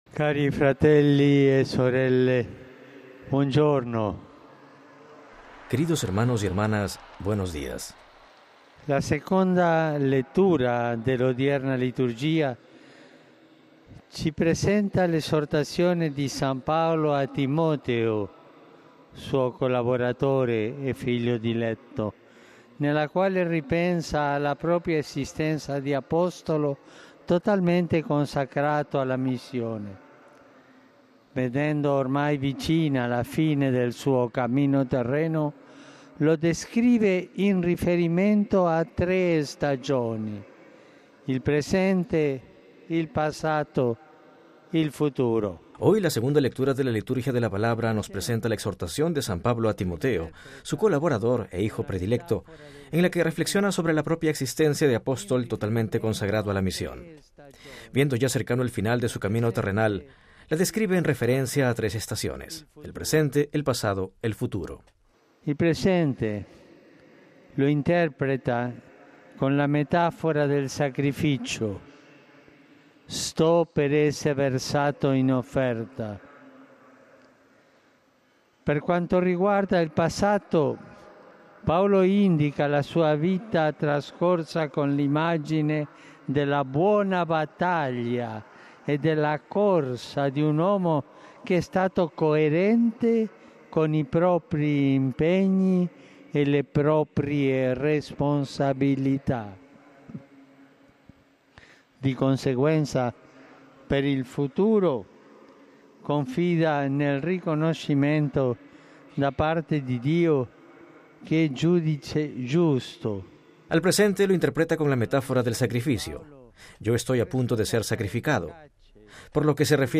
(RV).- El domingo de octubre en la que la Iglesia celebra la Jornada Mundial Misionera con el lema ‘Iglesia misionera, testimonio de misericordia’, el Papa Francisco dirigió el tradicional rezo del Ángelus delante de miles de peregrinos reunidos en la Plaza de San Pedro del Vaticano.
Palabras del Papa Francisco antes del rezo del Ángelus